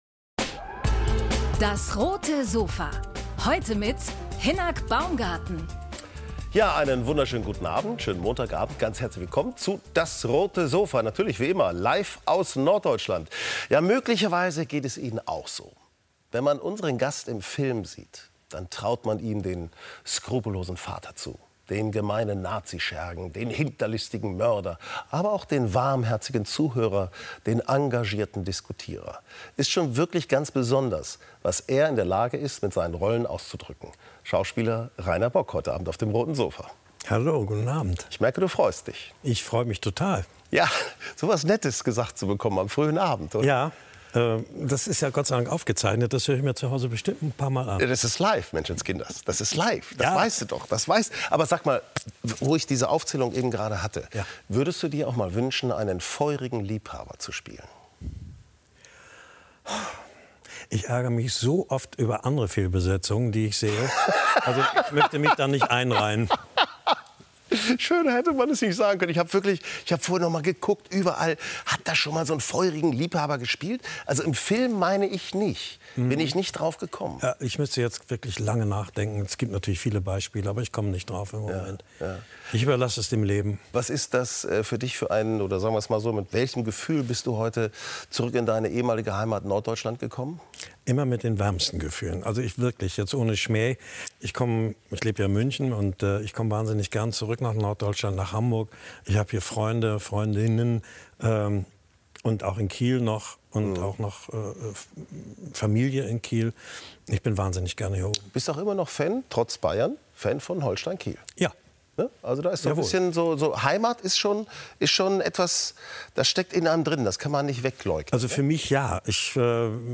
Er ist der Mann für die leisen und intensiven Töne: Schauspieler Rainer Bock ~ DAS! - täglich ein Interview Podcast